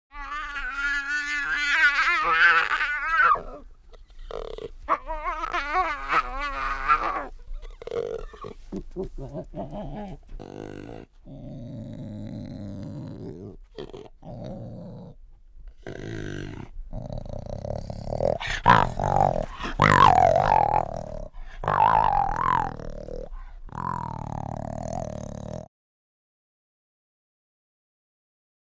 animal
Panther Cries with Purrs